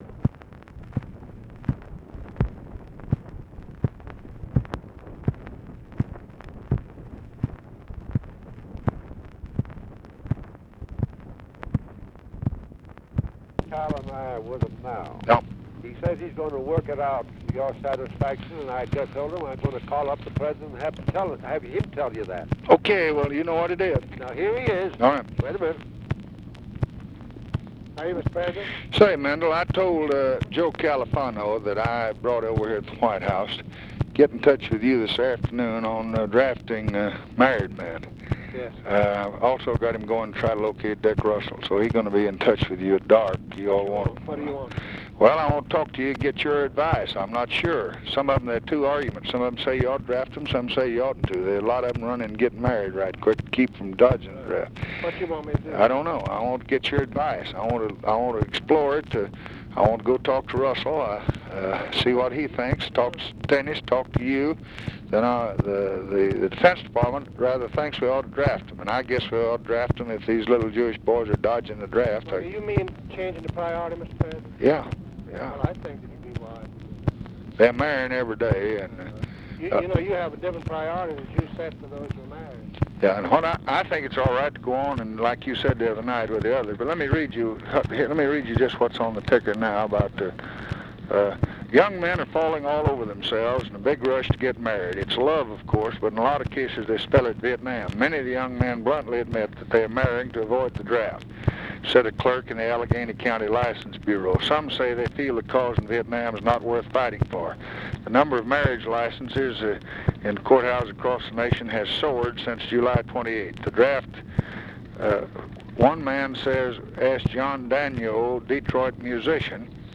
Conversation with JOHN MCCORMACK and MENDEL RIVERS, August 23, 1965
Secret White House Tapes